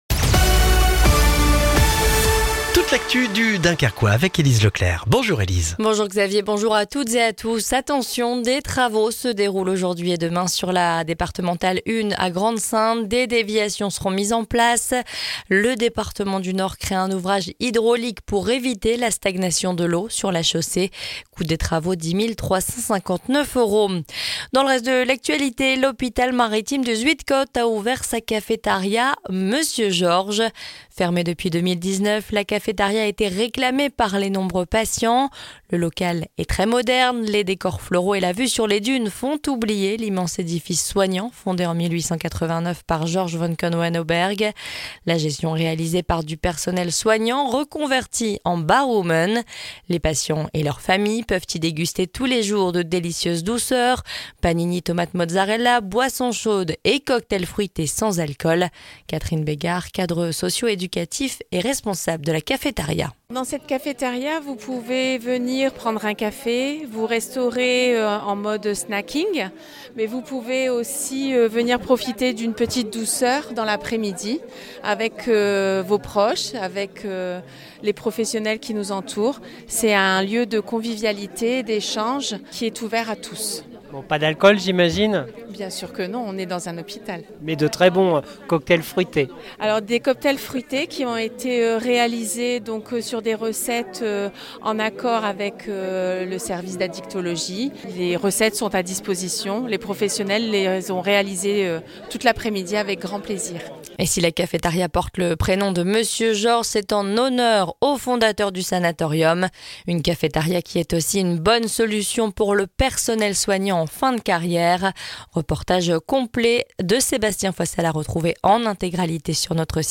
Le journal du jeudi 3 octobre dans le Dunkerquois